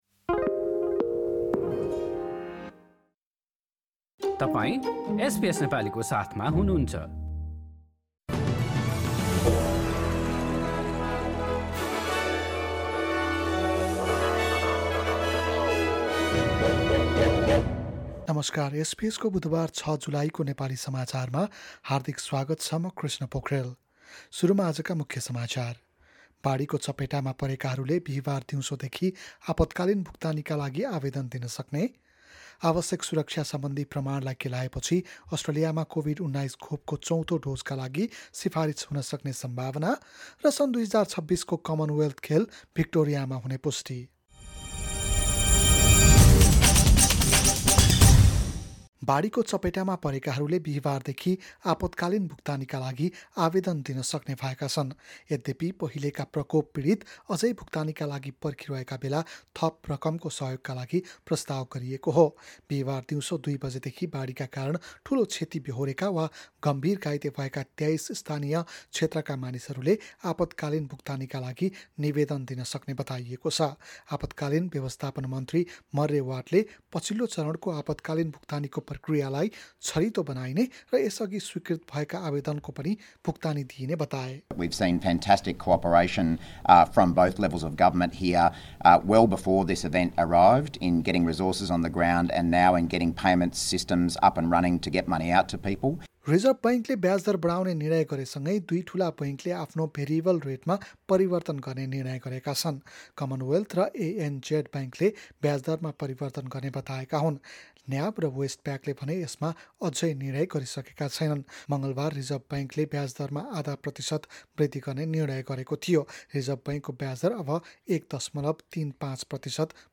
एसबीएस नेपाली अस्ट्रेलिया समाचार: बुधबार ६ जुलाई २०२२